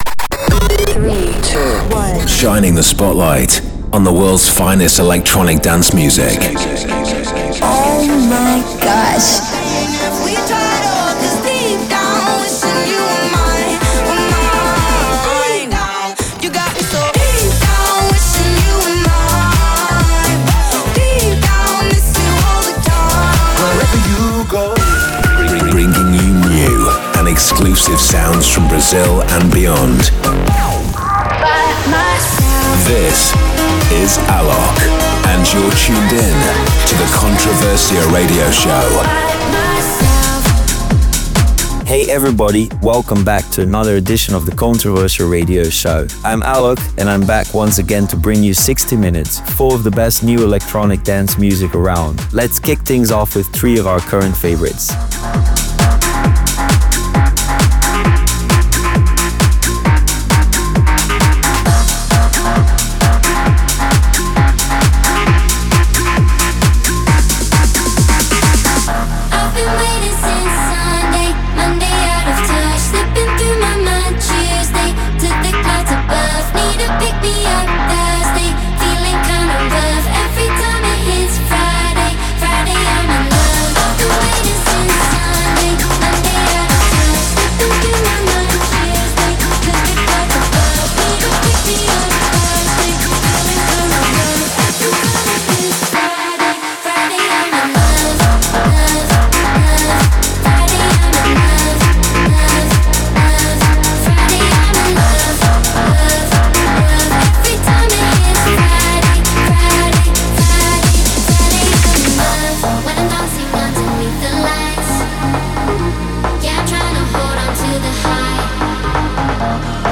DJ Mix & Live Set
genre: EDM , House